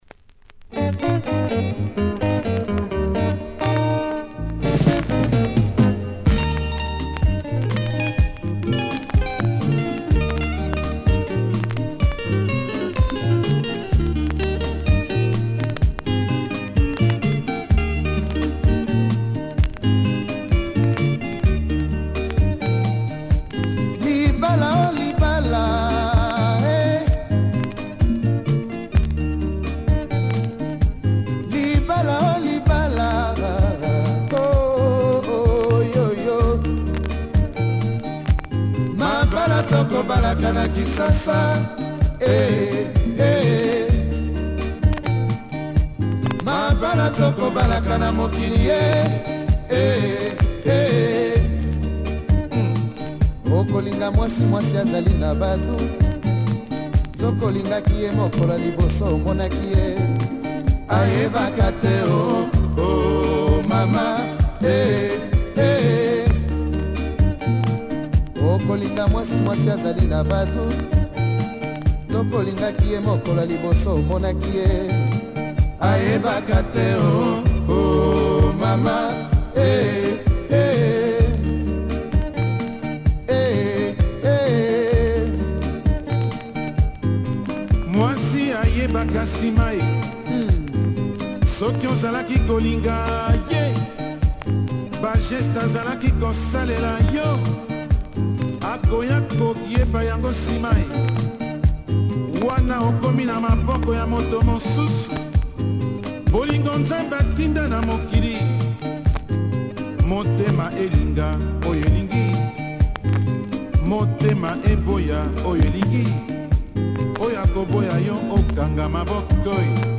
plays with some noise in the start, check audio.